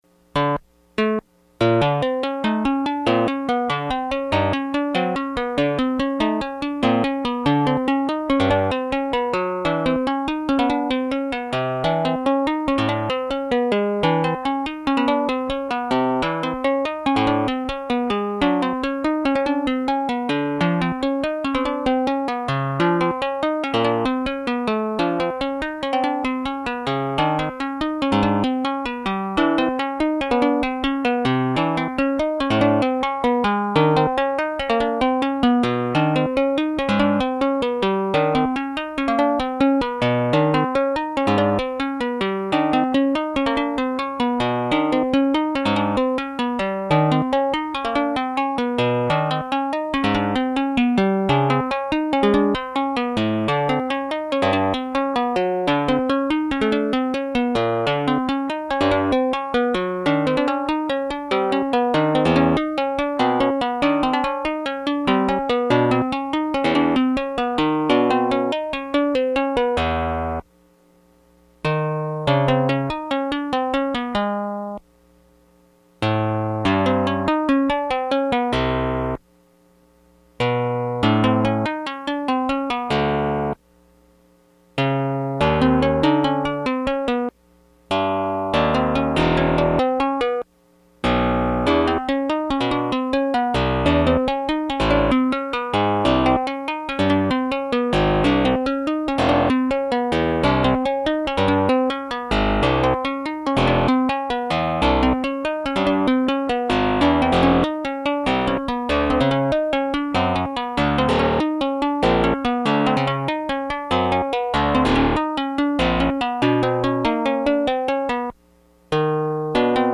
The following sound files demonstrate a prototype that I have developed in Lisp and Common Music (CM) for MIDI score generation.
I have begun work on a program which will ideally act as an engine for creating compositions in the style of Béla Bartók, and in particular, his solo piano music in Mikrokosmos.